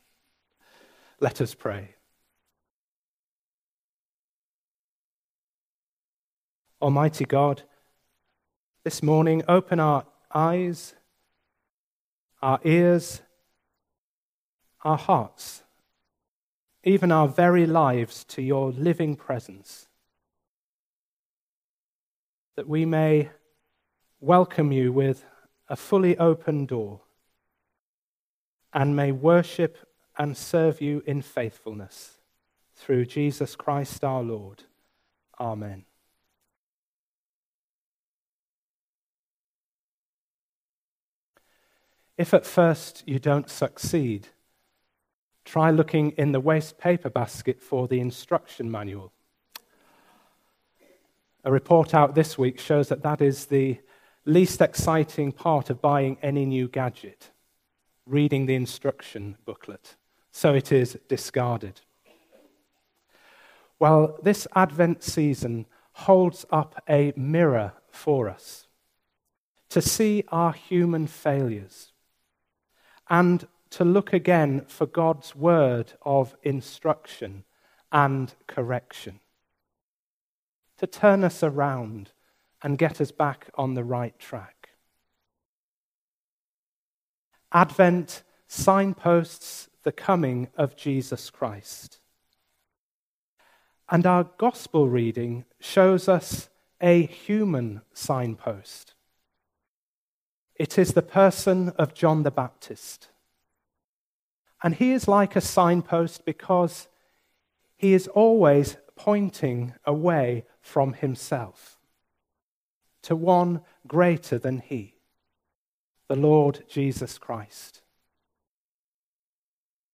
Luke 3:7-18 Service Type: Sunday Morning A service of morning worship in a contemporary style